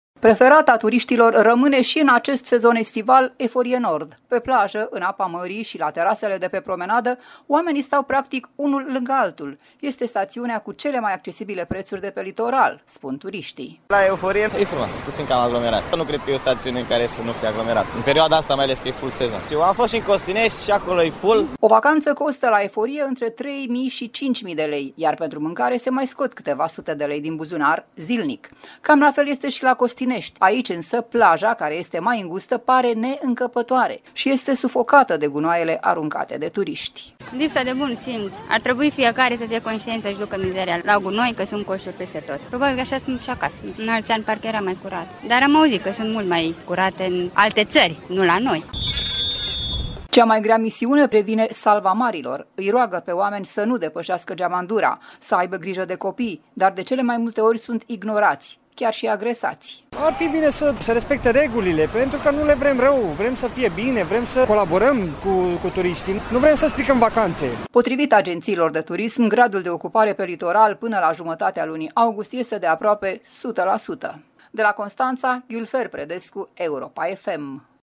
Am fost și în Costinești, dar și acolo e full”, spune un tânăr.
Dar am auzit că sunt mult mai curate, în alte țări, nu la noi”, declară, indignată, o femeie.